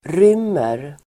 Uttal: [r'ym:er]